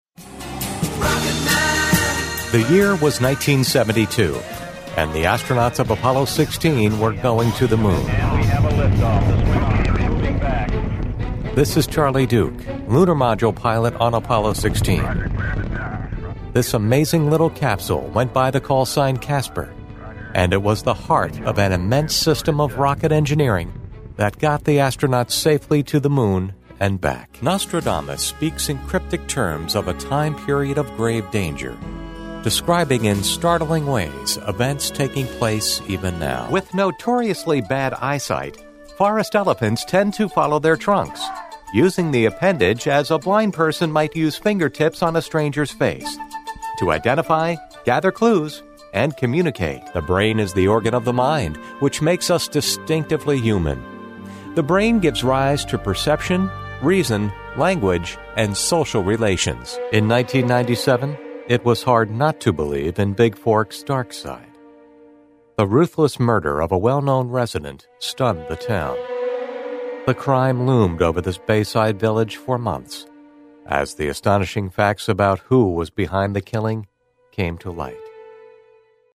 Energetic-Professional-Articulate-Sincere-Authoritative-Friendly-Familiar-Trustworthy-Inviting- Smooth-Crisp-Dynamic-Informative-Clear-Educational-Corporate-Intelligent.
mid-atlantic
Sprechprobe: Sonstiges (Muttersprache):
Private ISDN Studio.